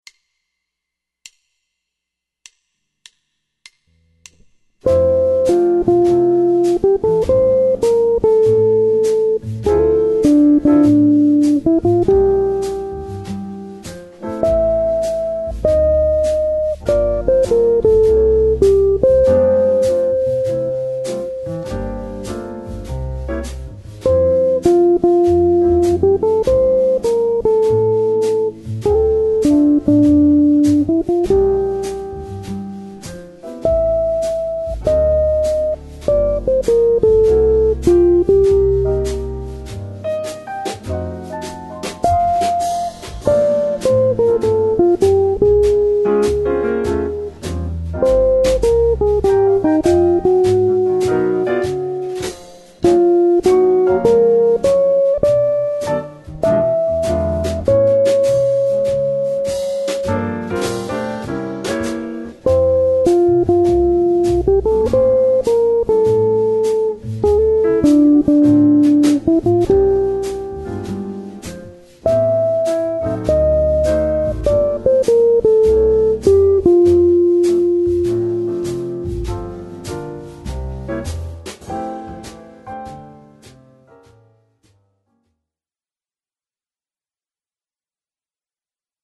After transposing the lead sheet to F Major, it seems to sit in a much more suitable range for a chord melody guitar arrangement, so let’s work on the tune in this key:
chord-melody-guitar-audio-example-2.mp3